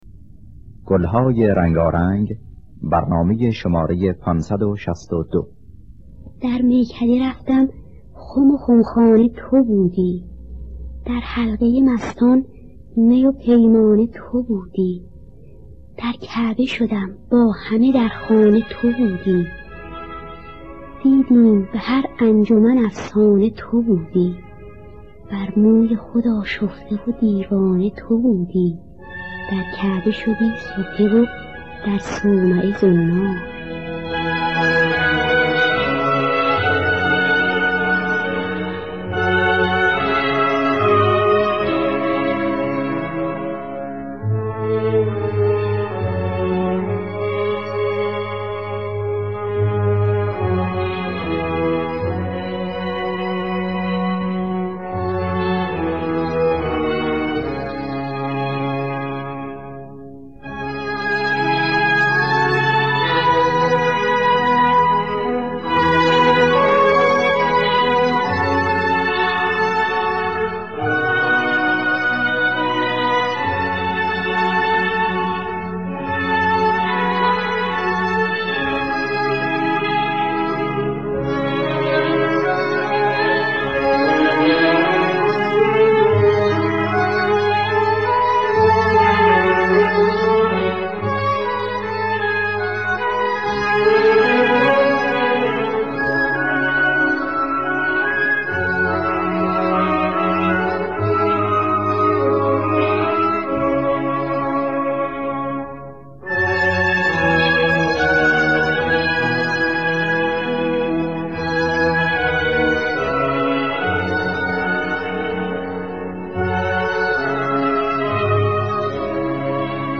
در دستگاه دشتی